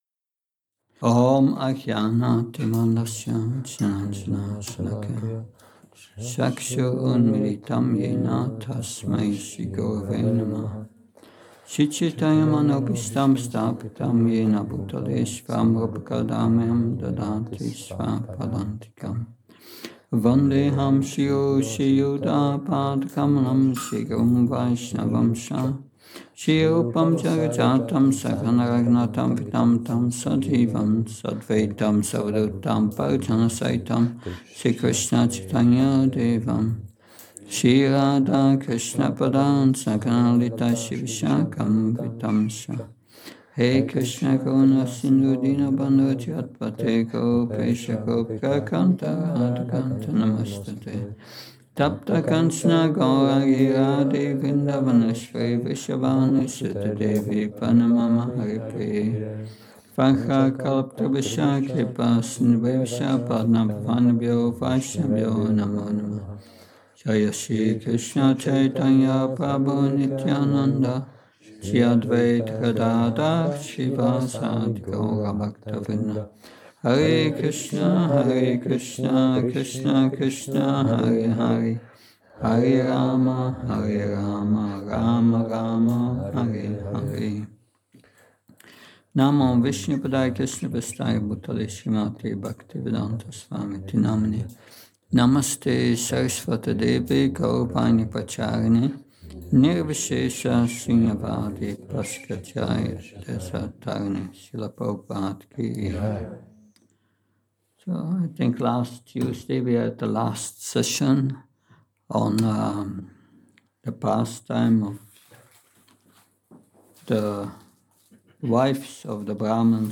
Šrí Šrí Nitái Navadvípačandra mandir
Seminář Zábavy Krišny ve Vrindávanu 3